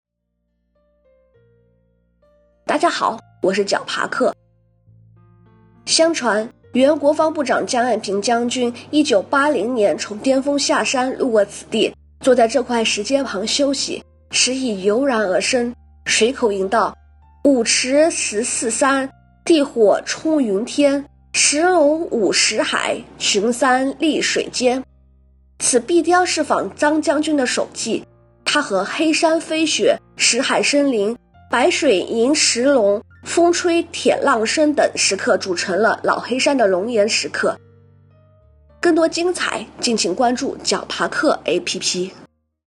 将军石----- 梦想不远啦 解说词: 相传，原国防部长张爱萍将军1980年从巅峰下山路过此地，坐在这块石阶旁休息，诗意油然而生，随口吟到：“五池十四山，地火冲云天，石龙舞石海，群山立水间”。